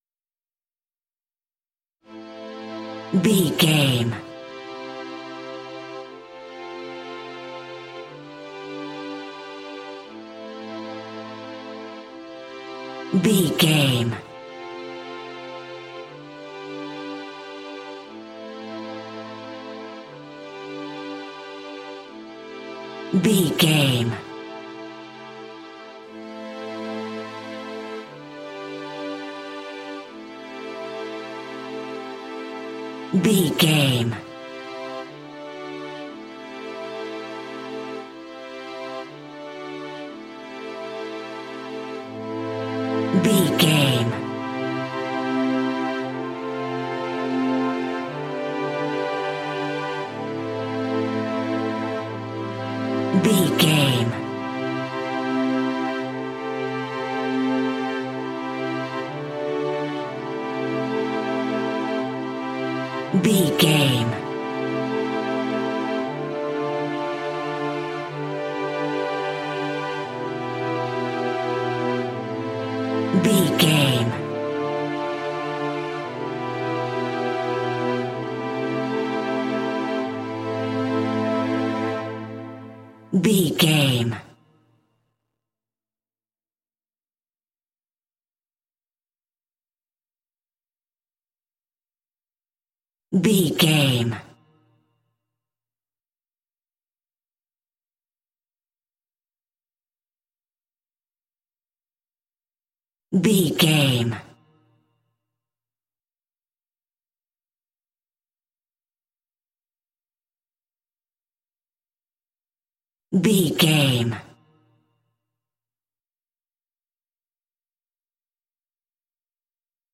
Aeolian/Minor
melancholy
contemplative
serene
peaceful
reflective
ambient
contemporary underscore